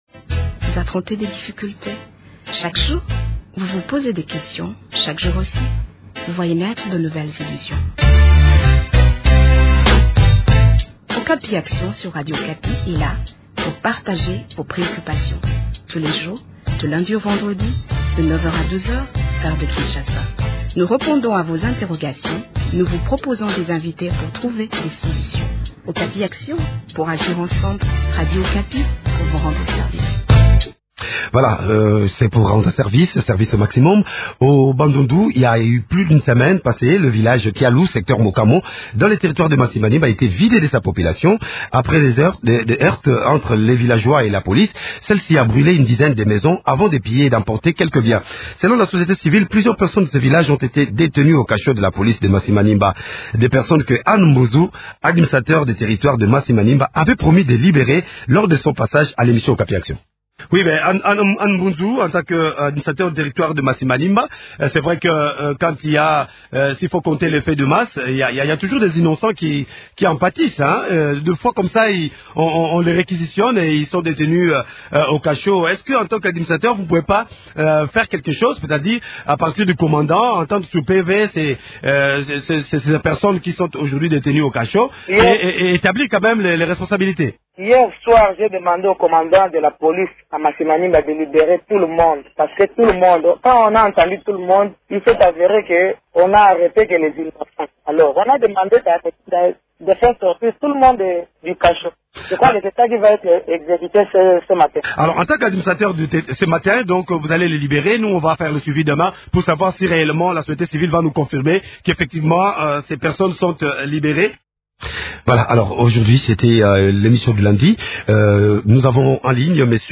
en parle avec Anne Mbunzu, administrateur de territoire Masimanimba.